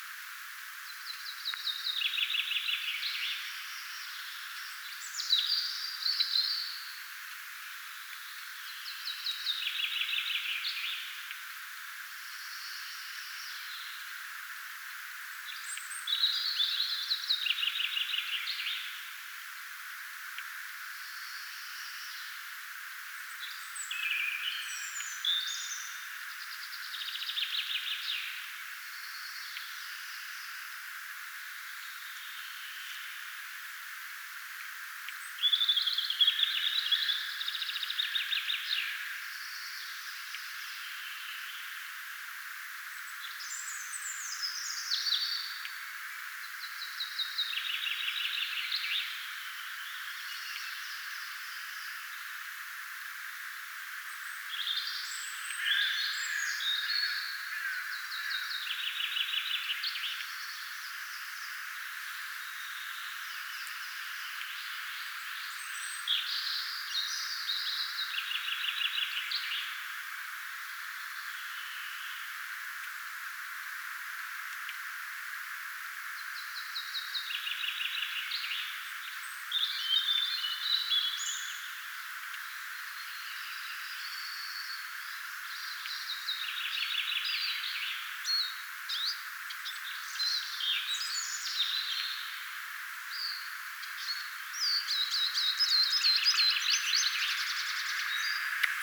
lyhyitä metsäkirvislinnun säkeitä taustalla
lyhyita_metsakirvislinnun_sakeita_taustalla.mp3